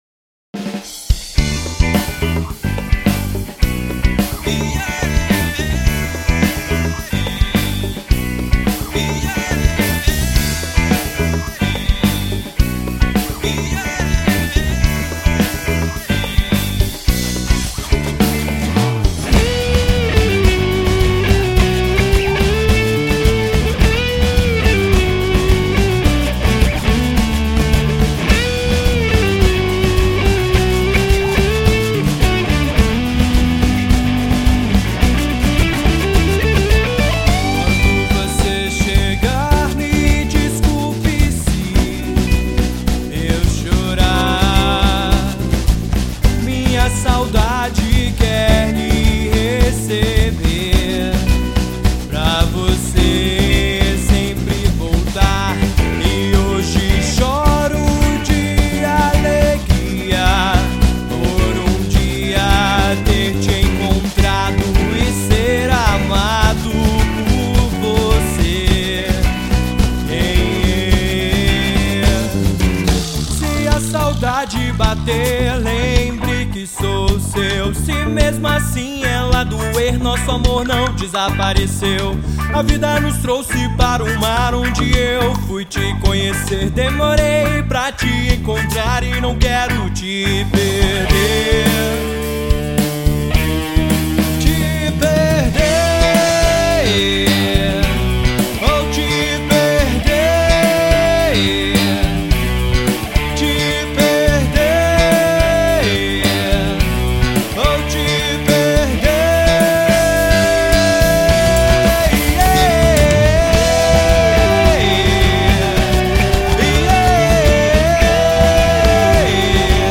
EstiloWorld Music